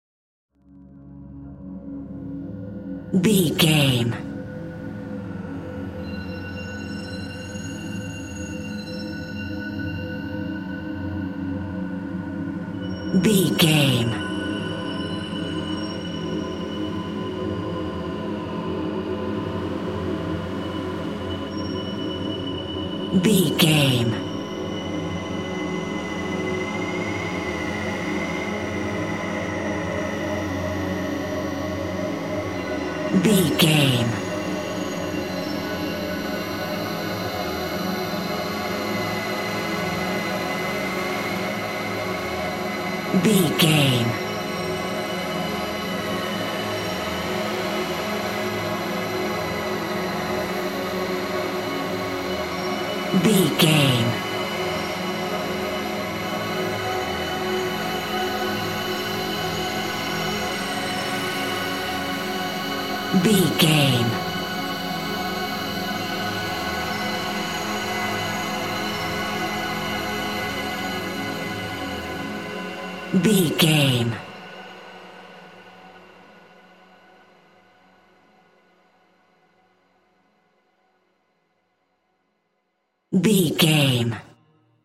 Thriller
Aeolian/Minor
Slow
scary
ominous
dark
suspense
haunting
eerie
synths
Synth Pads
atmospheres